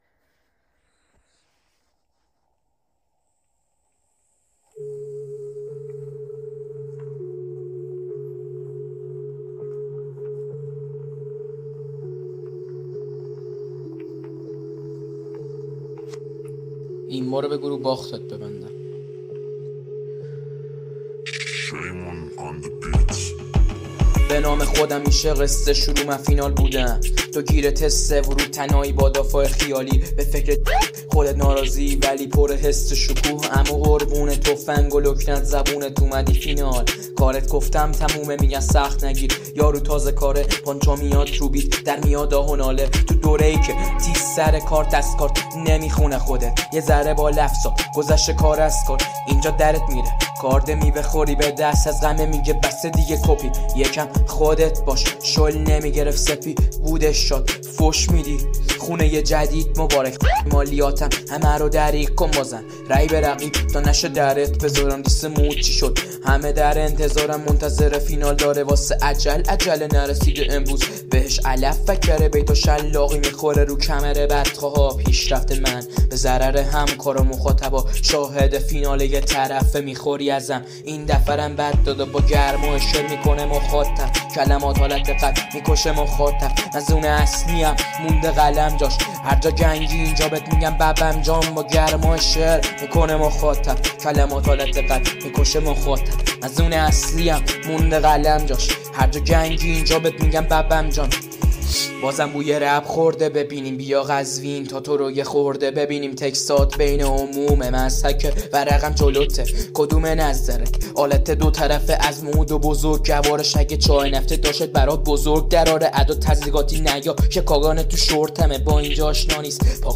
رپ بتل
persian rap battle